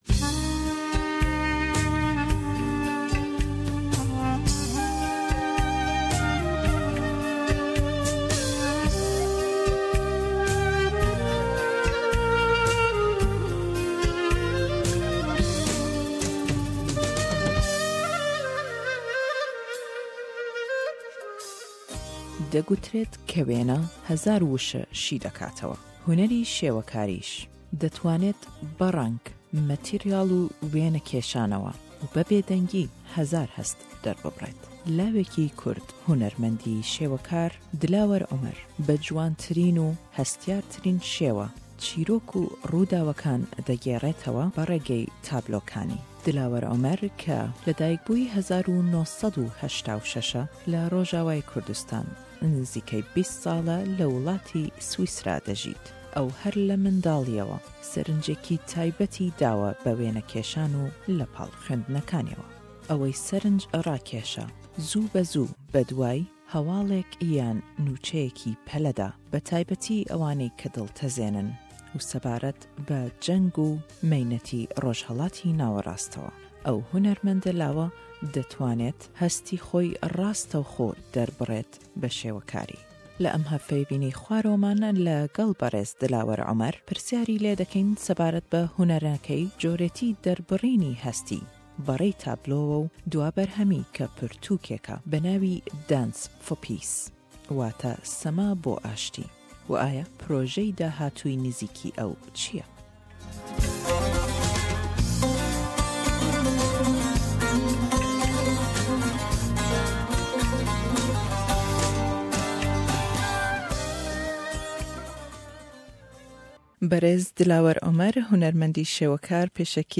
Muzîkî pêşgutin